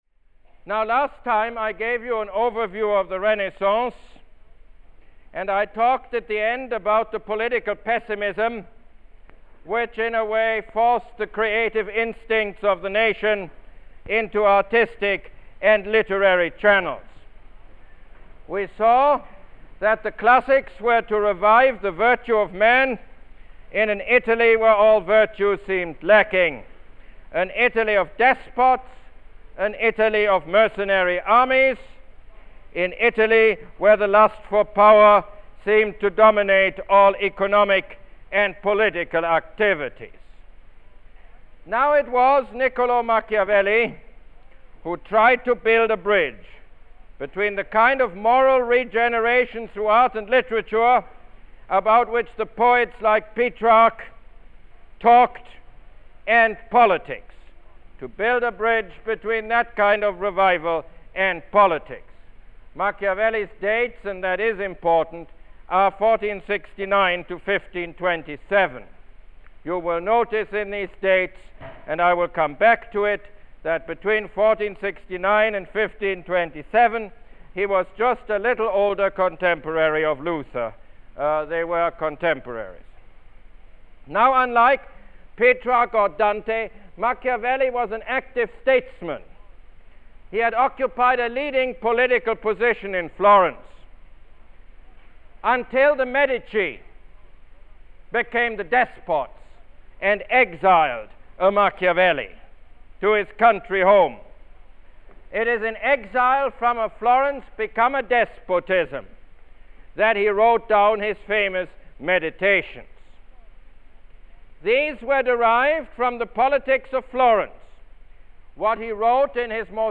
Lecture #3 - Machiavelli